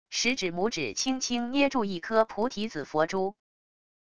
食指拇指轻轻捏住一颗菩提子佛珠wav音频